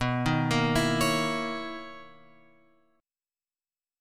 B Major 11th
BM11 chord {7 6 8 9 x 9} chord